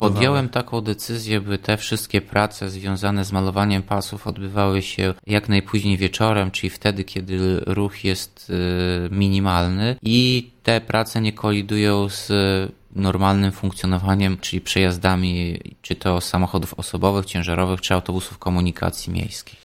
-Właśnie rozpoczęła się wiosenna akcja poprawy oznaczenia poziomego na ełckich ulicach, mówi Tomasz Andrukiewicz – prezydent Ełku.